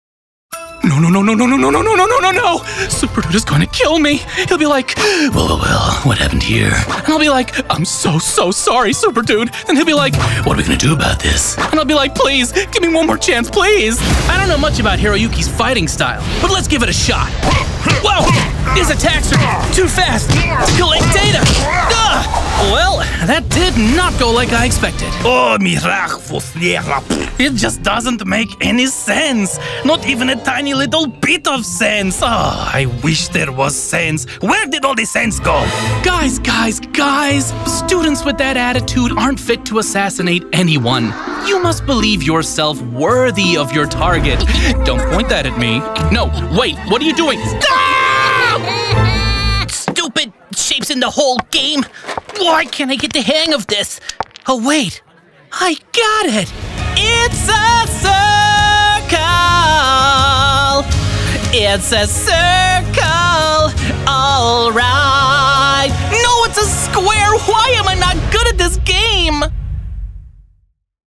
Warm, friendly, guy-next-door voice companies like Samsung and McDonalds are using!
british rp | natural french | international german | natural irish | natural irish | natural russian | natural scottish | natural southern us | natural spanish iberian | natural standard us | natural welsh | natural